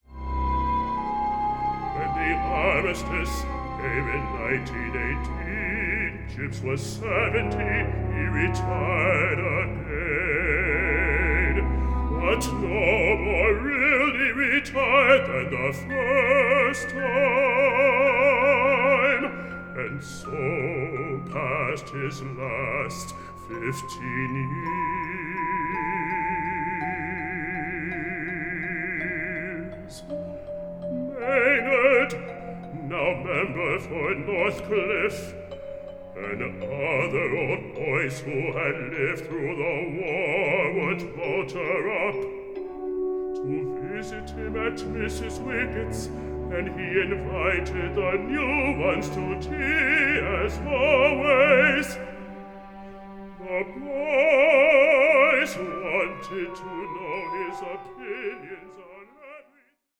A FEEL-GOOD OPERA ABOUTTHE TEACHER WE ALL WISH WED HAD
new studio recording